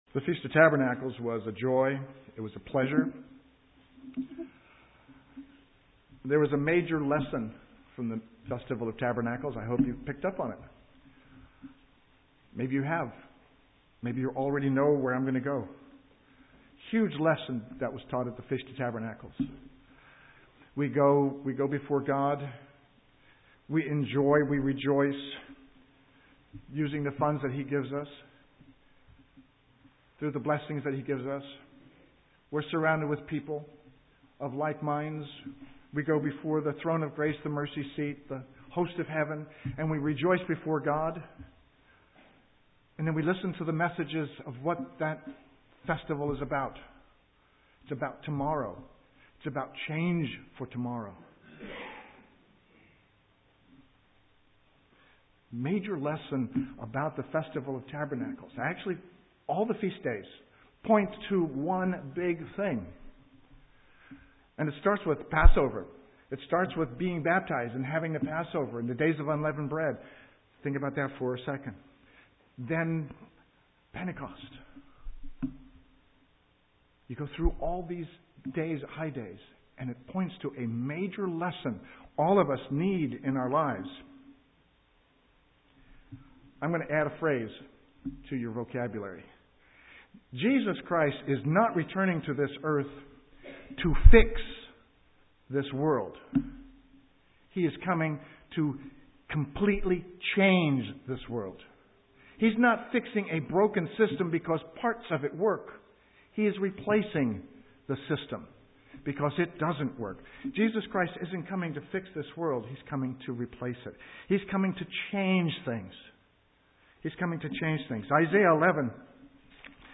Given in Eureka, CA
UCG Sermon Studying the bible?